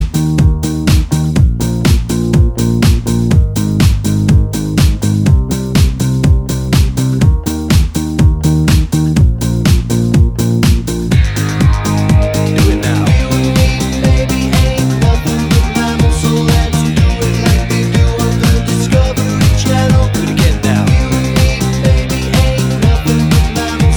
With Intro Voiceover Pop (1990s) 3:51 Buy £1.50